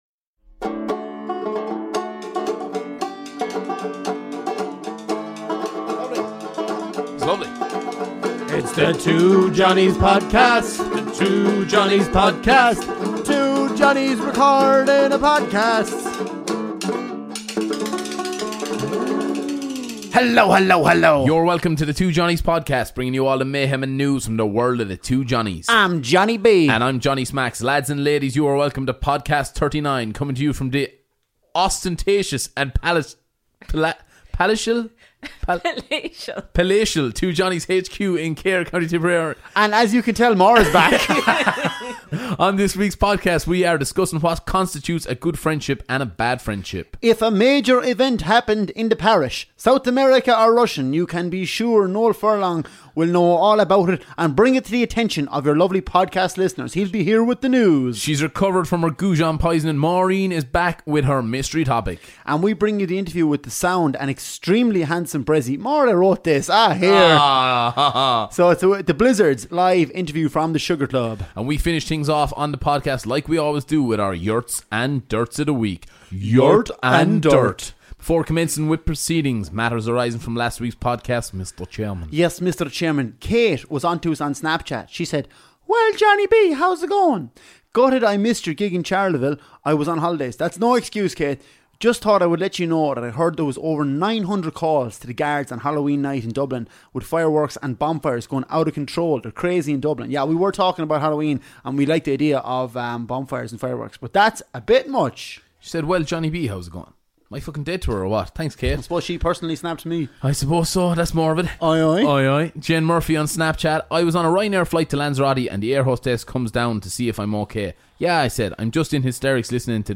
Interview with Irish rocker band The Blizzards.